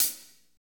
Index of /90_sSampleCDs/Northstar - Drumscapes Roland/DRM_Fast Rock/KIT_F_R Kit Wetx
HAT F R H1GR.wav